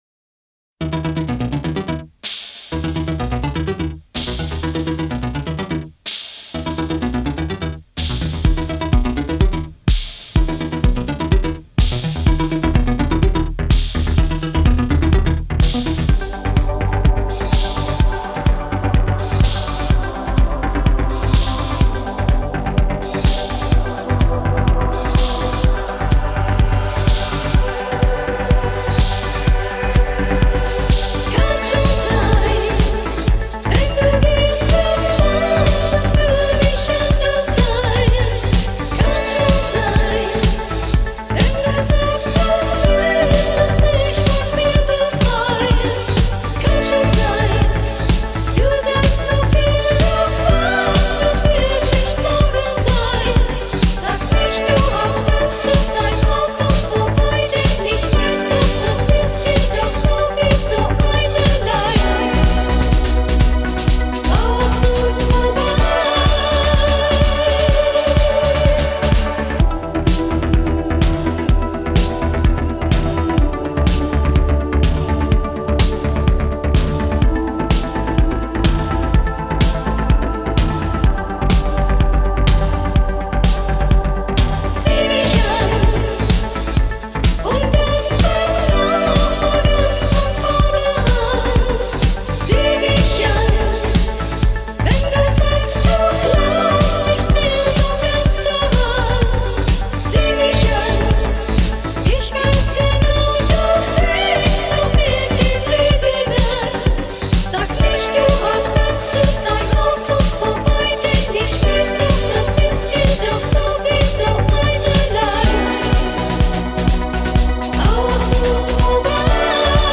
80's minimal electronic, or stuff that sounds like it.